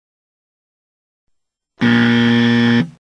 Wrong Buzzer Soundboard: Play Instant Sound Effect Button
The Wrong Buzzer sound button is a popular audio clip perfect for your meme soundboard, content creation, and entertainment.